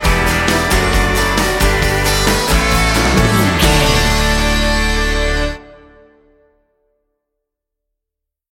Ionian/Major
D
electric guitar
drums
bass guitar
violin